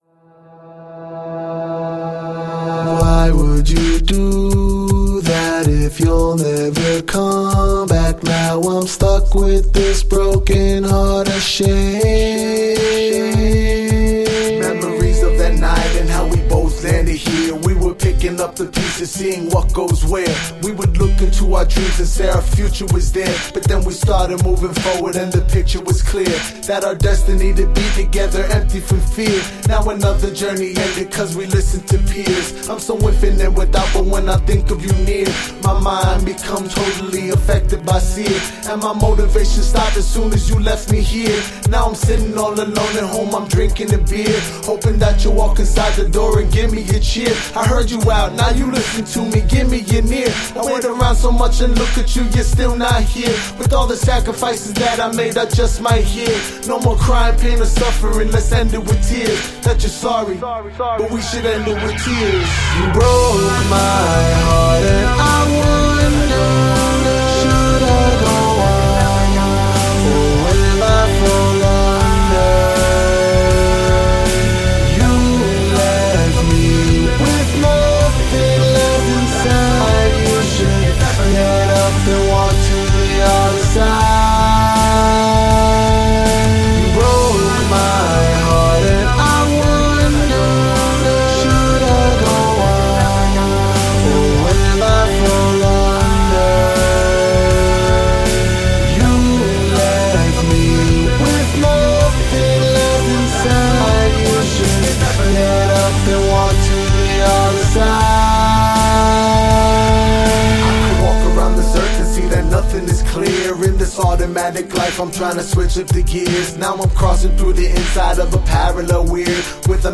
Hip-hop
Rock & Roll
Rhythm & Blues